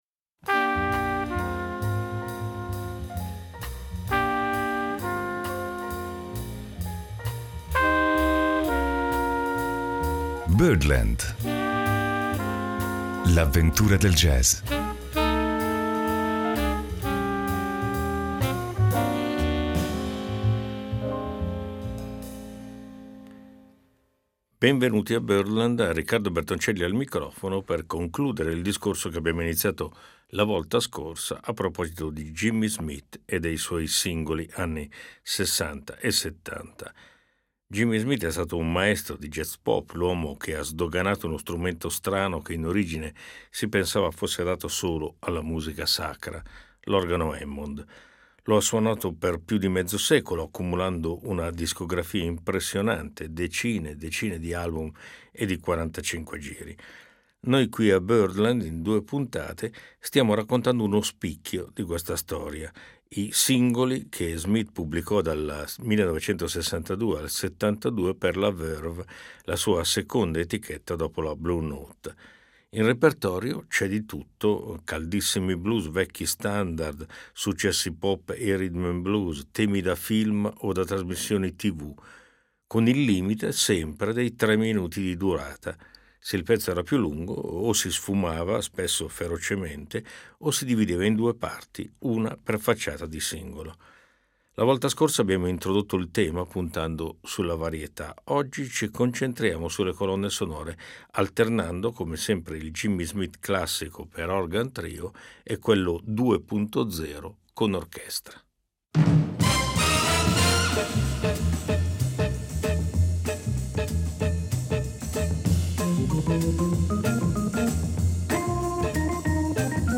L’organo Hammond di Jimmy Smith ha segnato un’epoca.